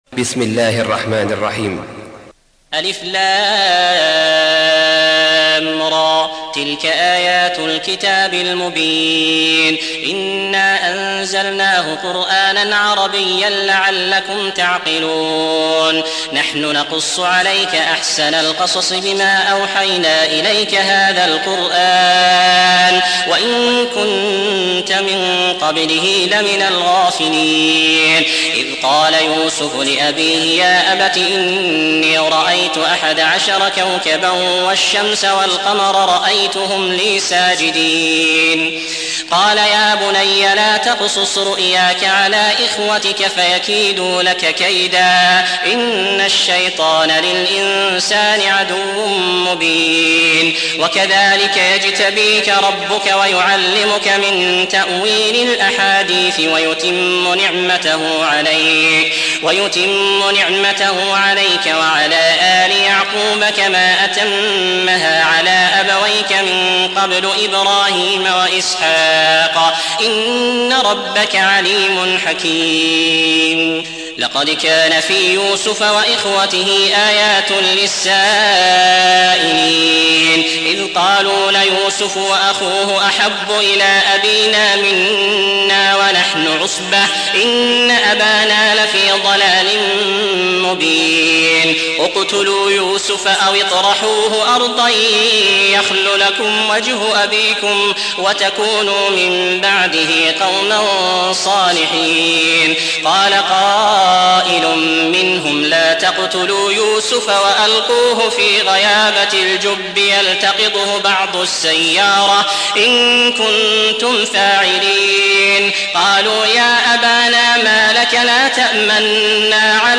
12. سورة يوسف / القارئ